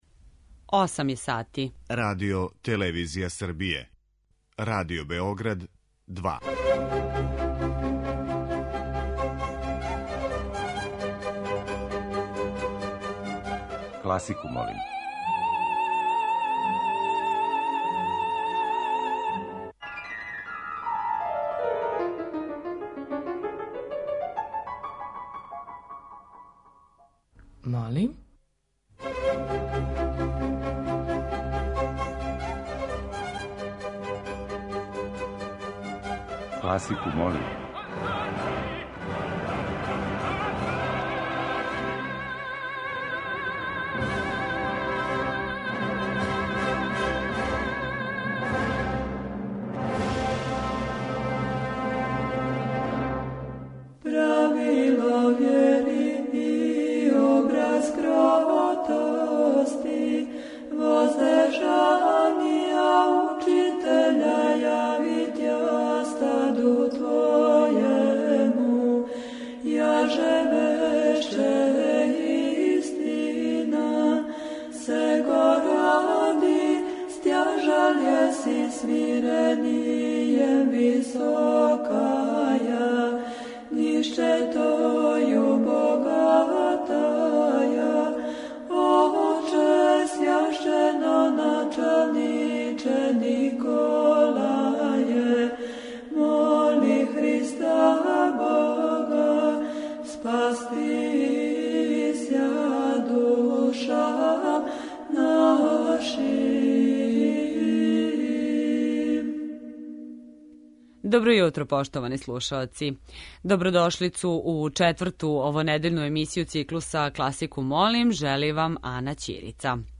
Уживо вођена емисија Класику, молим окренута је широком кругу љубитеља музике. Садржај је разноврстан, а огледа се у подједнакој заступљености свих музичких стилова, епоха и жанрова.